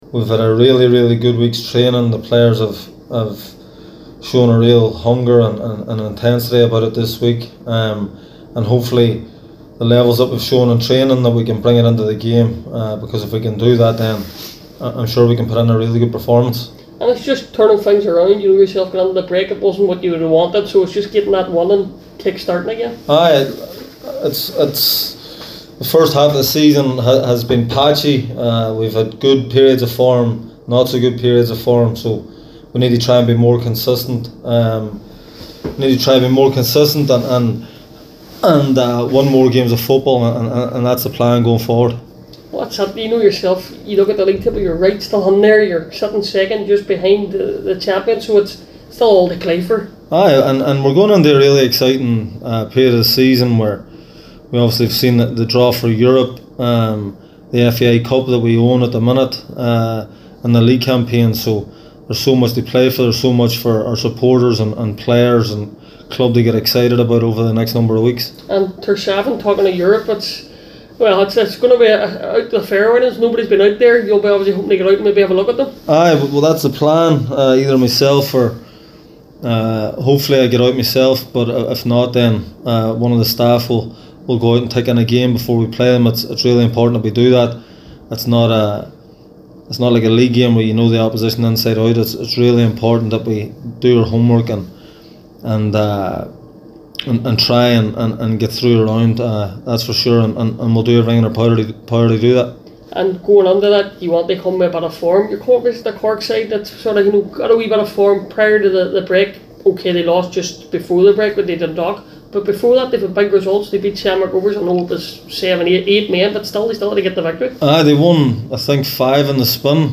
he spoke with the media ahead of the game.